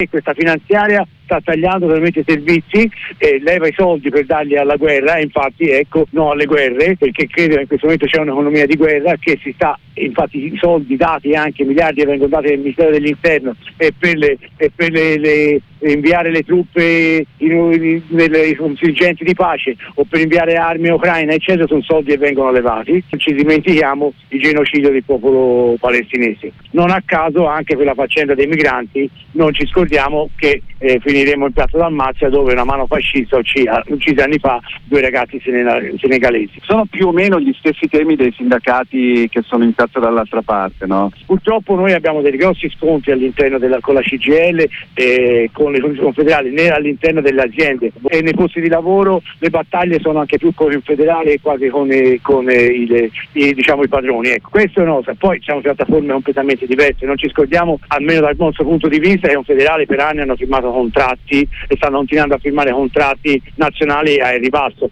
Il corteo dei Cobas